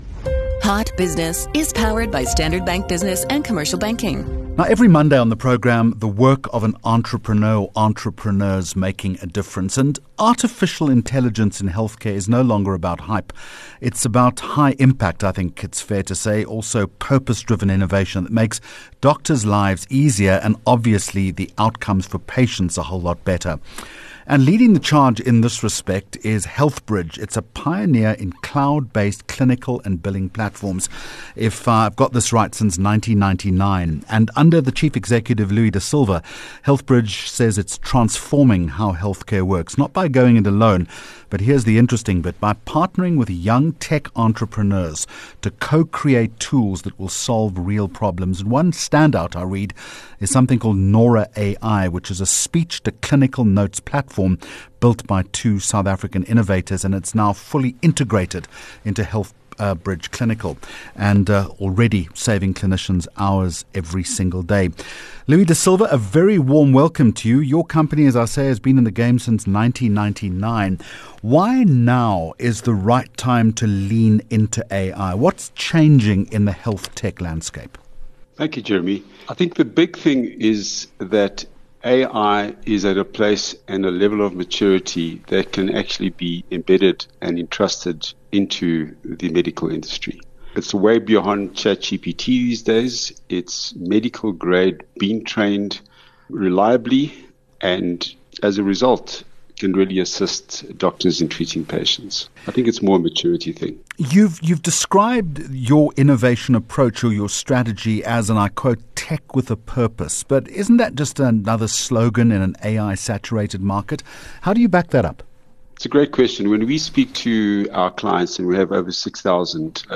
7 Jul Hot Business Interview